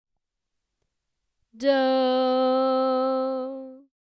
Oh Susannah Chords with Sung Tonic
The scale for this song is C Major pentatonic.